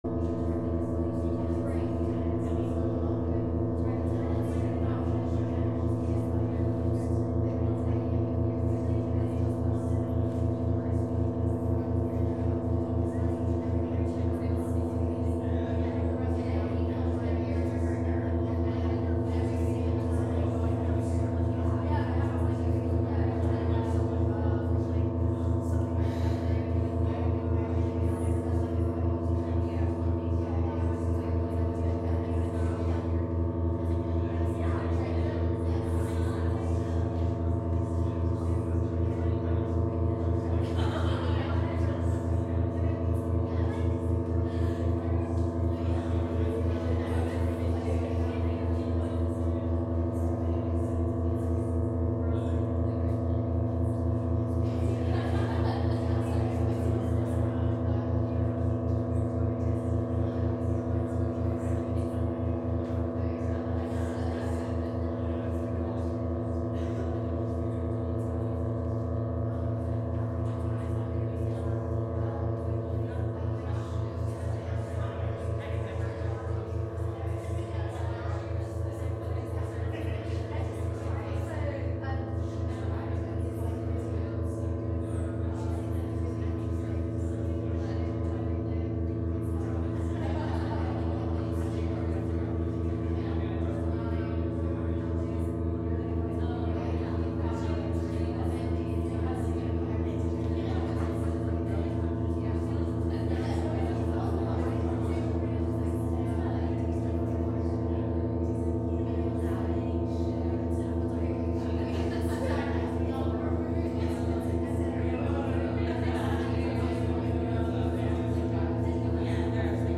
live from the CT::SWaM Plasticity Office at Fridman Gallery